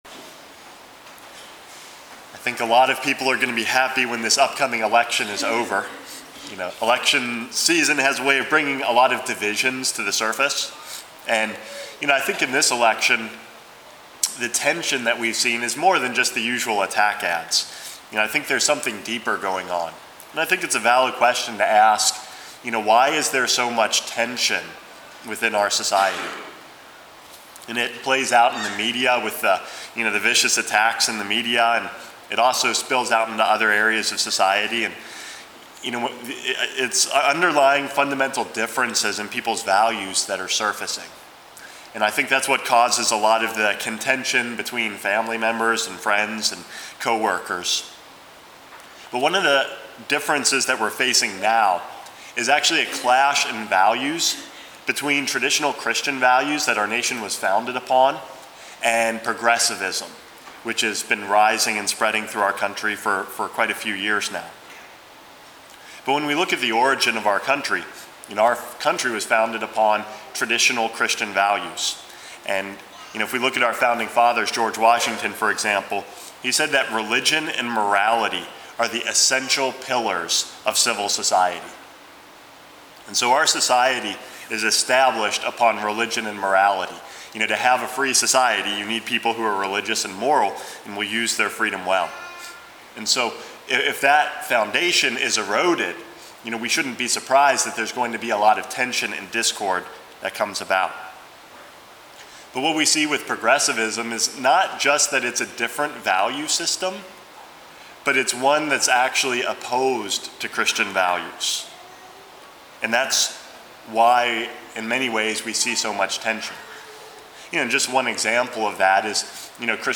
Homily #419 - From Conflict to Unity